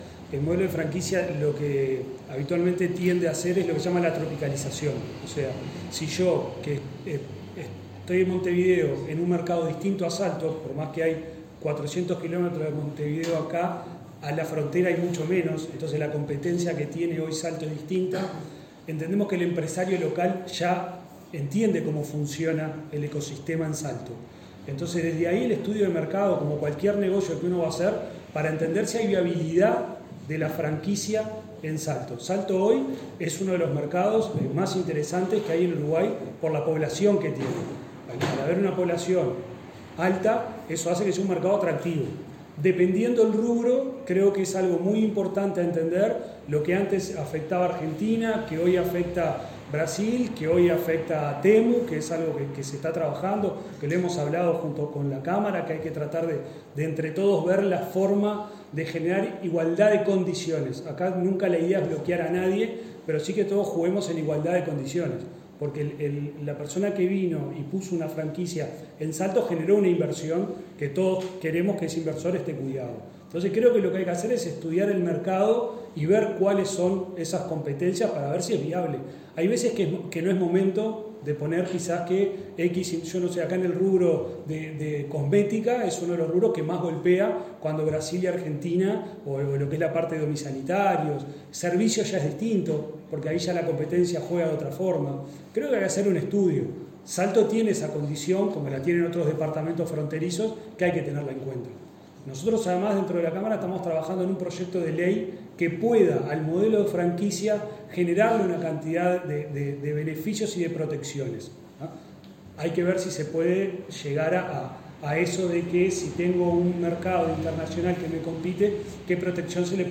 Este martes 29 de junio se realizó la conferencia de prensa de lanzamiento del Foro de Negocios Salto 2025, que se llevará a cabo el próximo 12 de agosto en el Centro Comercial e Industrial.